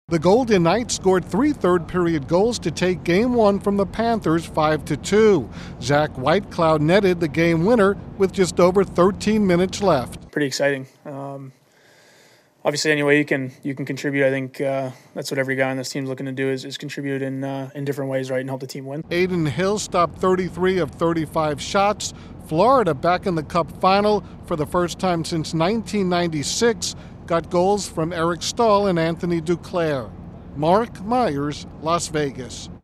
The Golden Knights have the edge through Game 1 of the Stanley Cup final. Correspondent